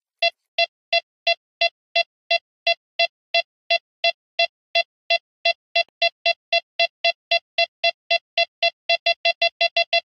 bomb_timer.ogg